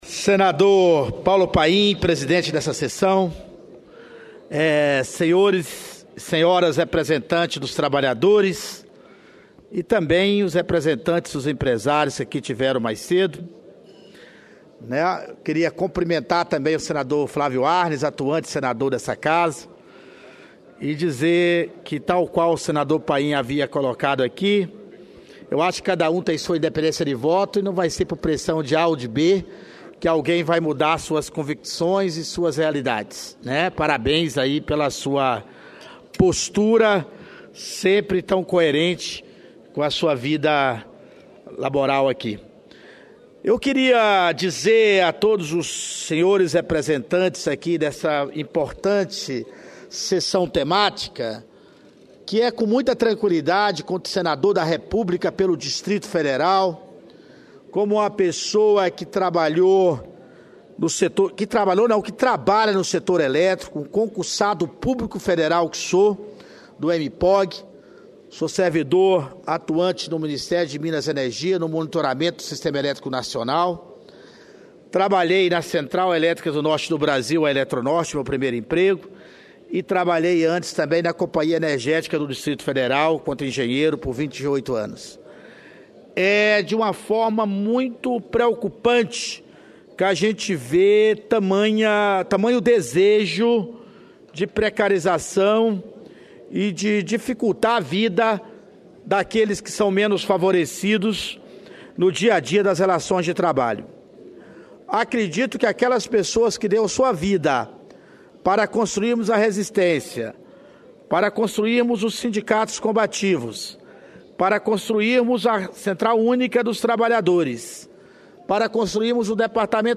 Pronunciamento do senador Hélio José